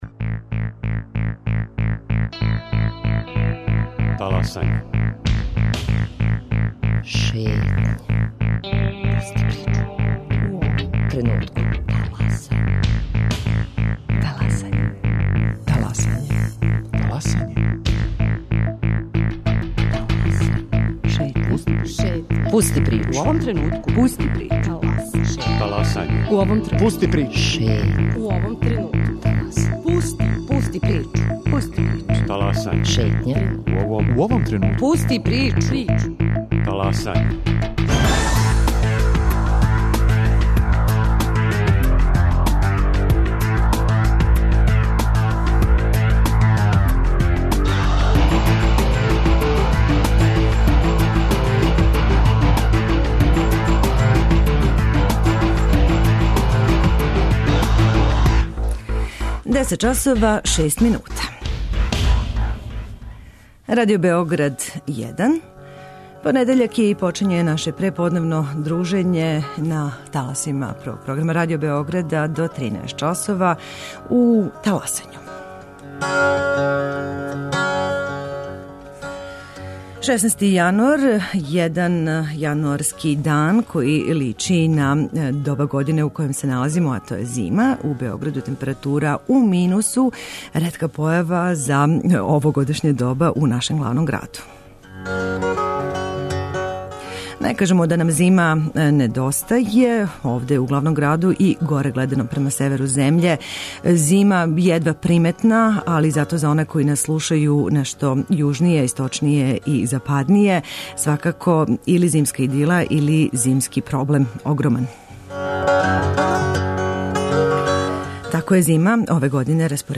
До 11 часова, као и увек понедељком, отворићемо наше телефоне за слушаоце питајући их - знају ли шта је то енергетска ефикасност, обновљива енергија и штеде ли енергију само из економских или и из еколошких разлога?